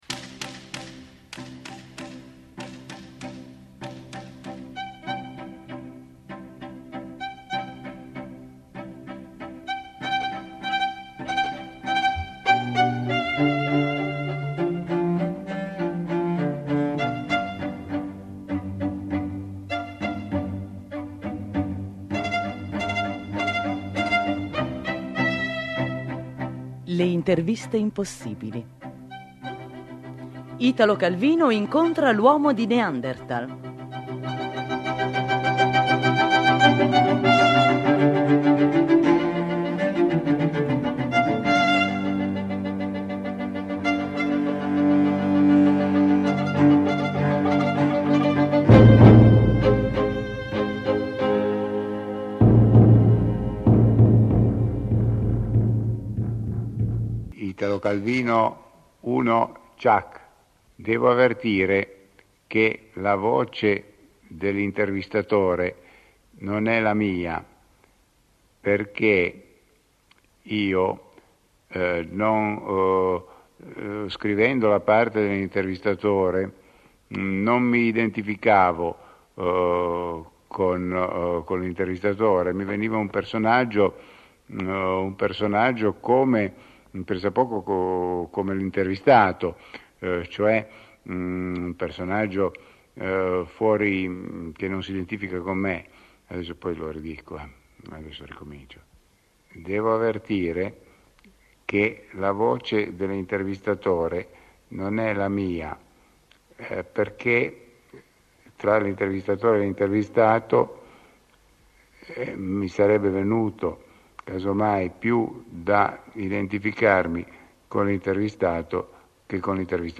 Dalle interviste impossibili: Italo Calvino intervista l'Uomo di Neanderthal